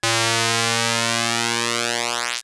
Hum39.wav